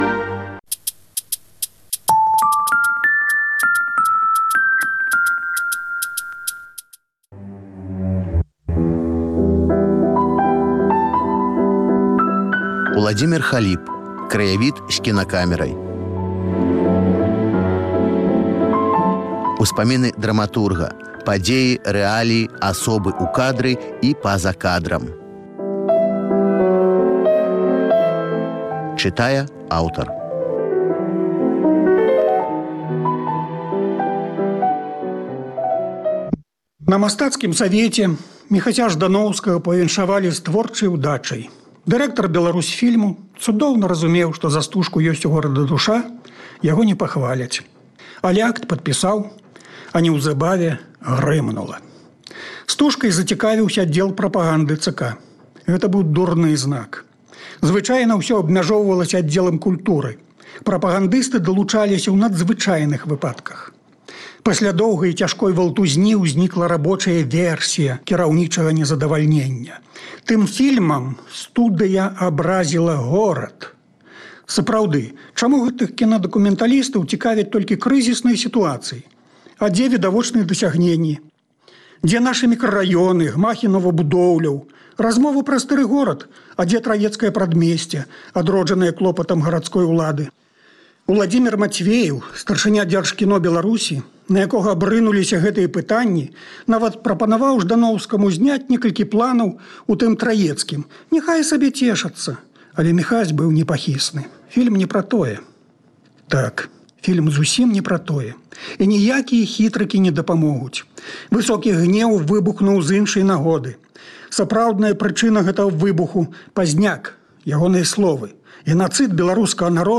У чытальнай залі Свабоды - успаміны ў аўтарскім чытаньні.